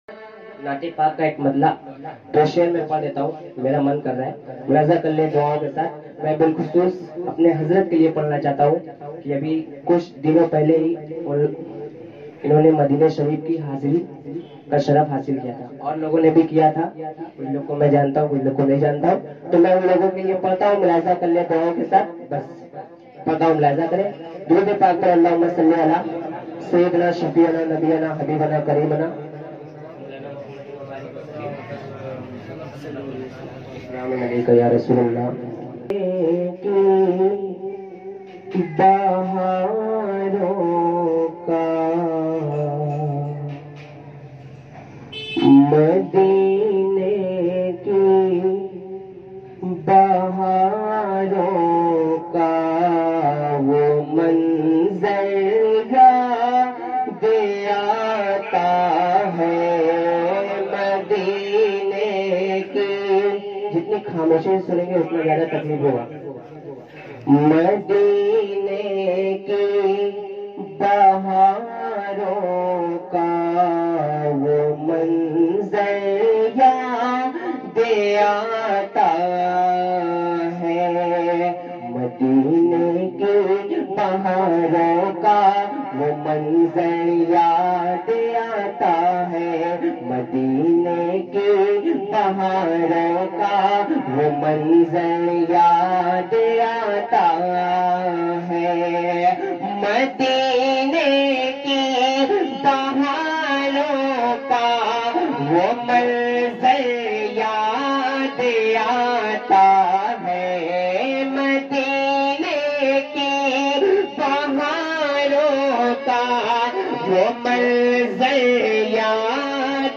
Naat